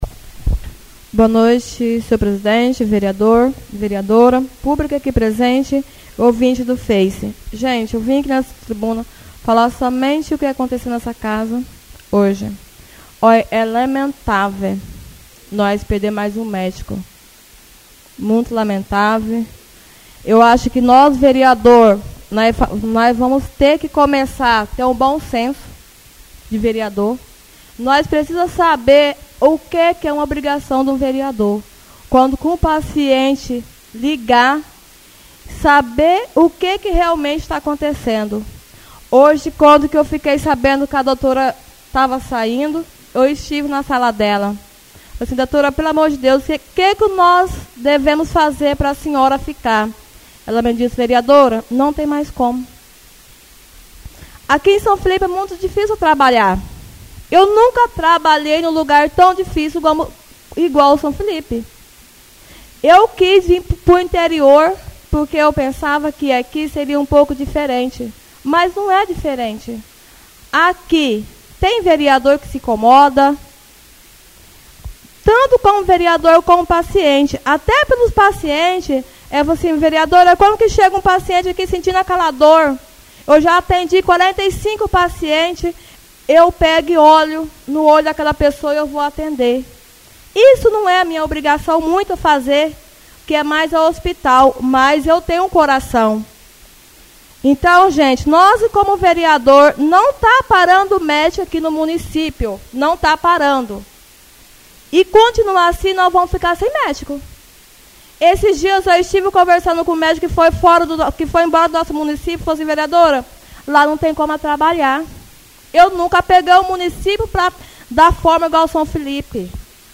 Oradores das Explicações Pessoais (28ª Ordinária da 3ª Sessão Legislativa da 6ª Legislatura)